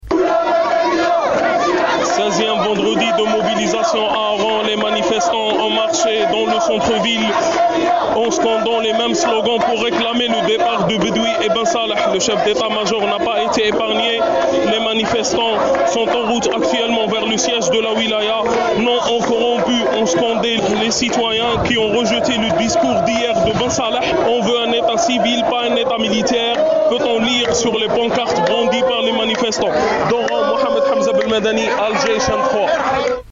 Compte rendu depuis Oran